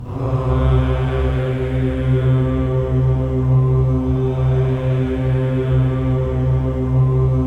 VOWEL MV02-L.wav